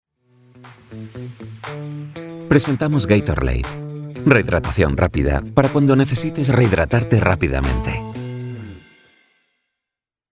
Male
Madrid nativo
Microphone: Neumann Tlm 103